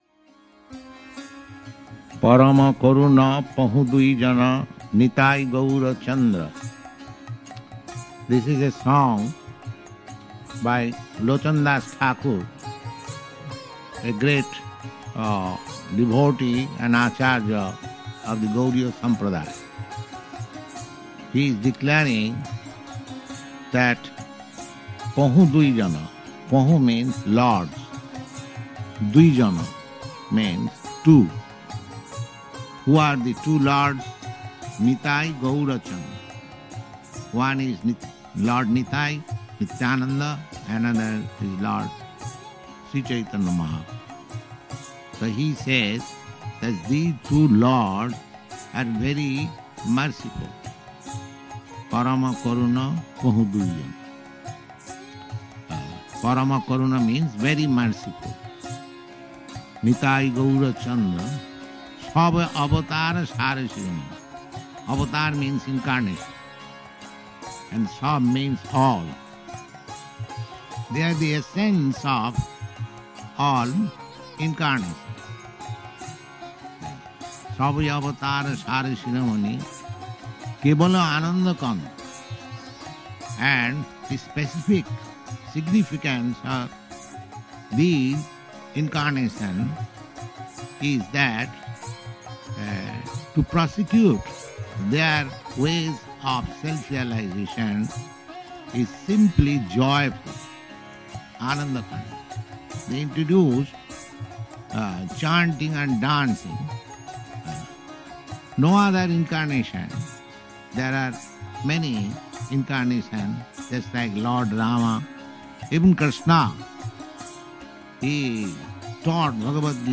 (690104 – Lecture Purport to Parama Koruna – Los Angeles)